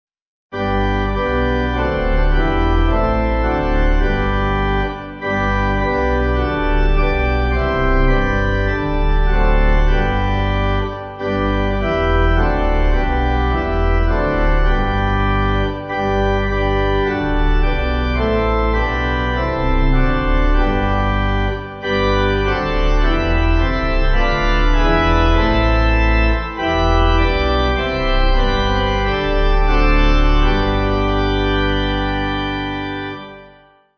Organ
(CM)   4/G